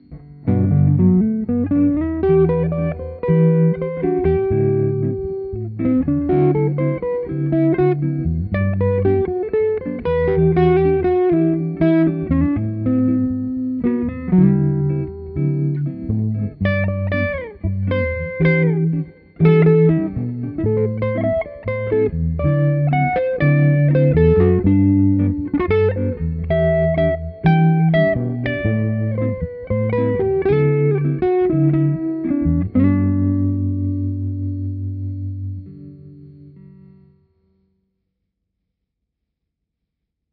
TwinR Guitar Amplifier – From clean to overdriven, the classic one.
TwinR is a faithful component-level model of one of the absolute most famous and sought-after tube guitar amplifiers in history.